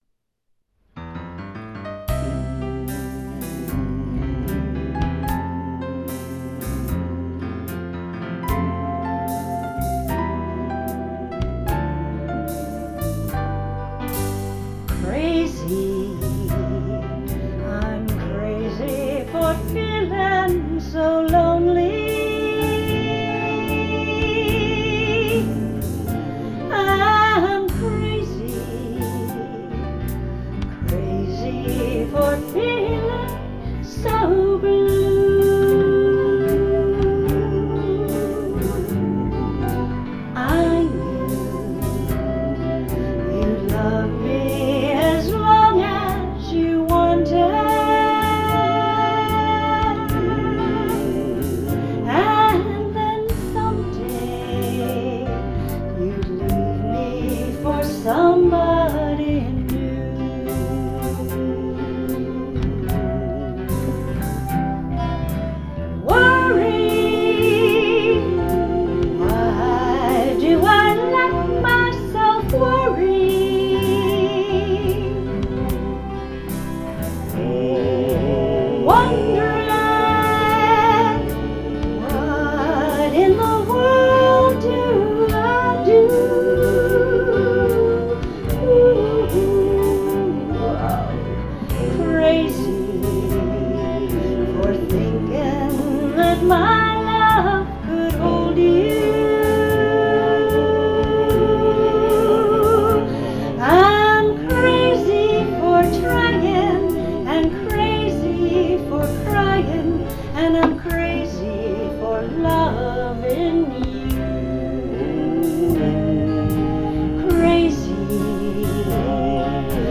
2:52 - k:C